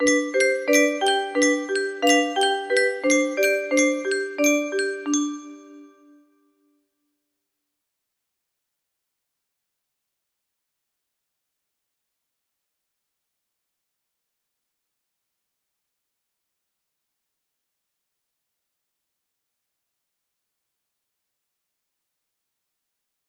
just trying out the intro